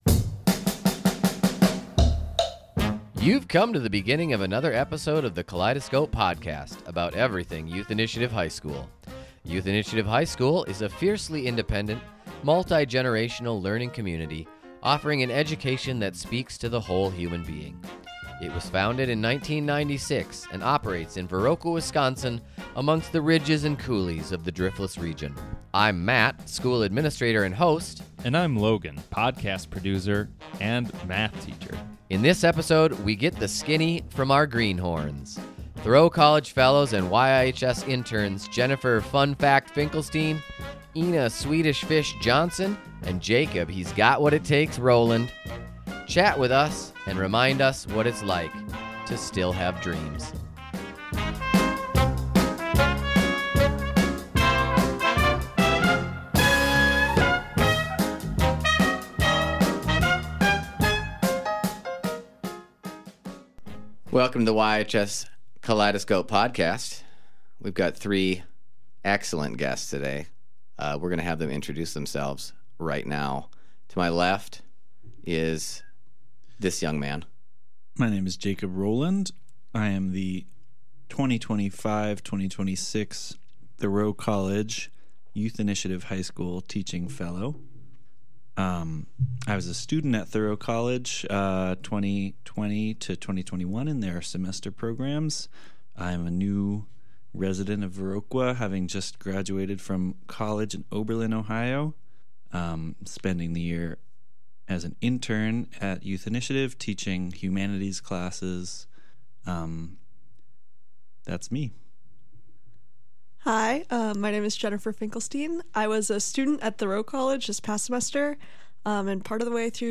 This month, we chat with Three YIHS Interns to hear their fresh perspective on our funny little high school.